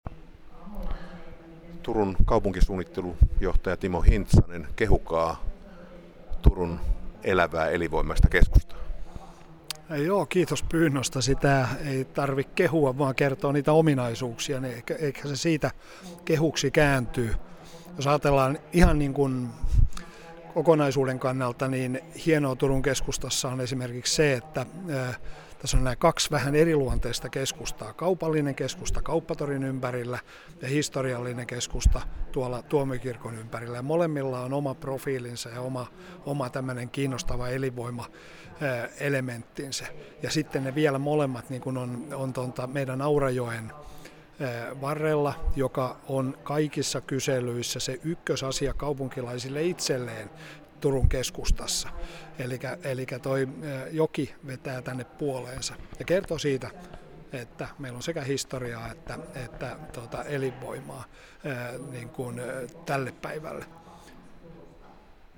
Paikka oli Forum korttelin keskusaula.